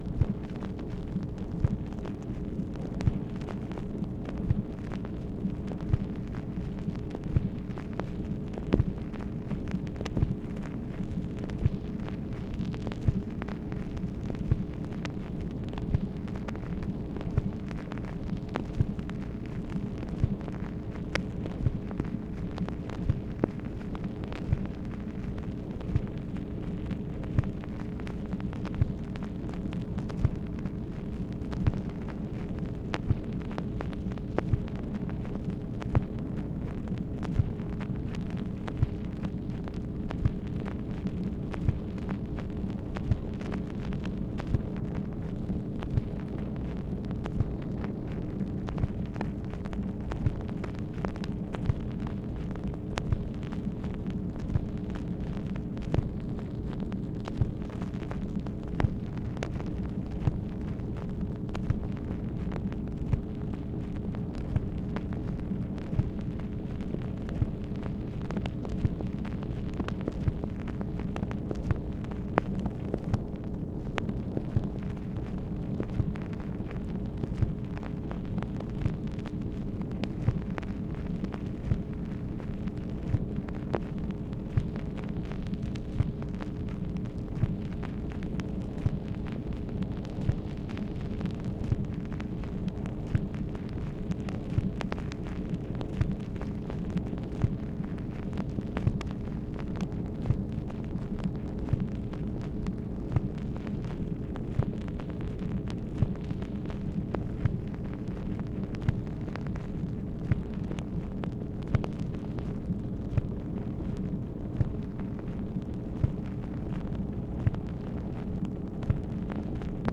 MACHINE NOISE, October 10, 1964
Secret White House Tapes | Lyndon B. Johnson Presidency